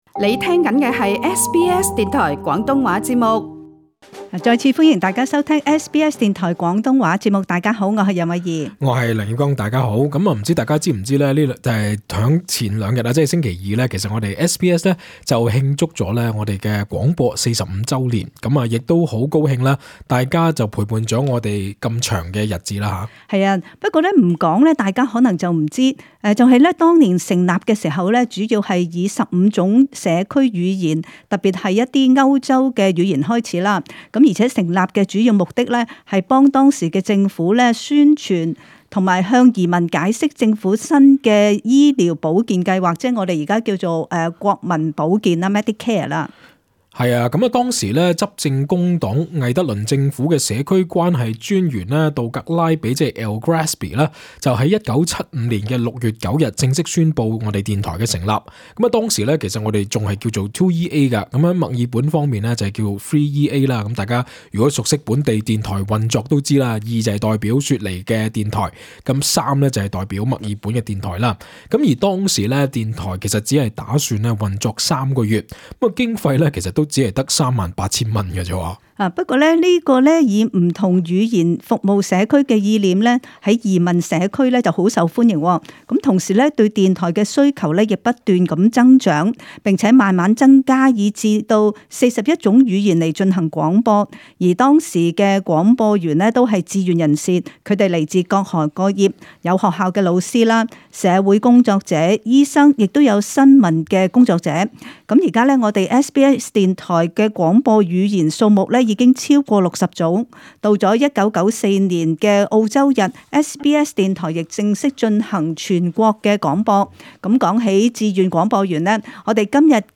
同大家一齊同我哋回顧SBS電台過去45年嘅趣事，亦有很多聽眾打電話入嚟同我哋講吓對SBS電台以及廣東話節目嘅睇法，有唔少聽眾係同我哋SBS電台一齊長大，多謝大家聽眾朋友嘅支持同鼓勵。